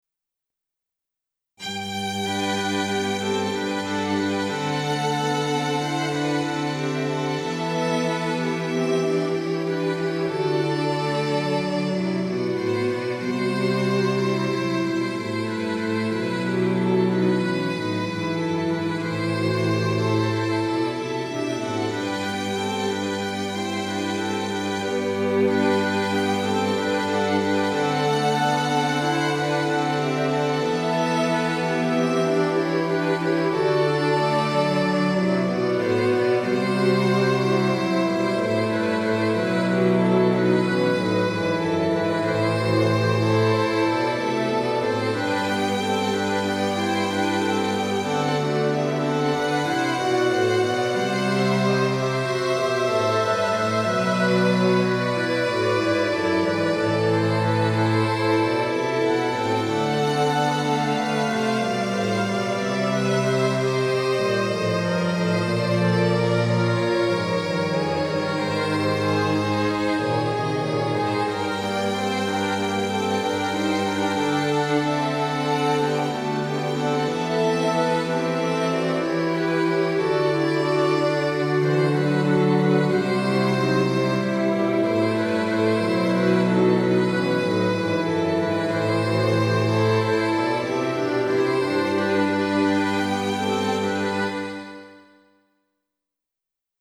Midi
♪Instrumental ensemble (Fl-Fl-Cl-Fg-Str)